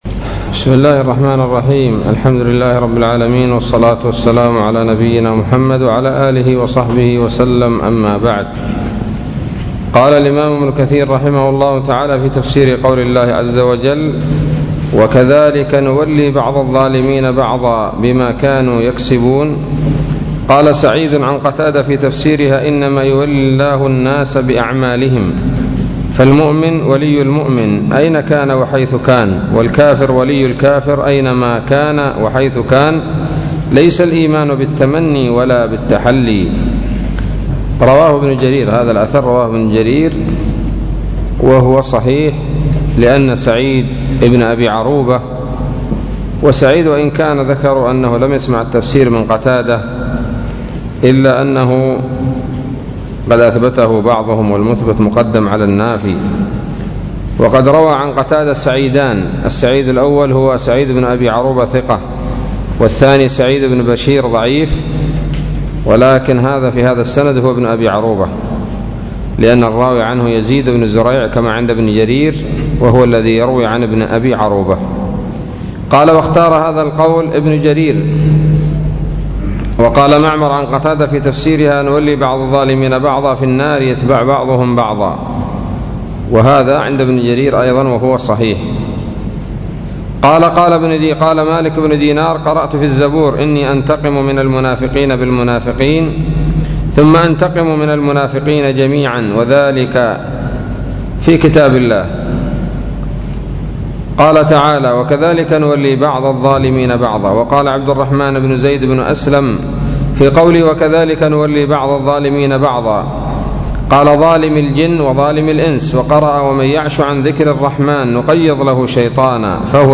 الدرس الخمسون من سورة الأنعام من تفسير ابن كثير رحمه الله تعالى